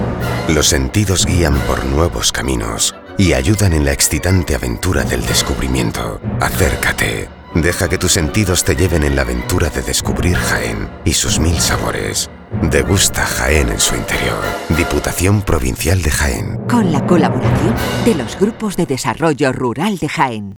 Cuxa_radio_Degusta_Jaxn.wav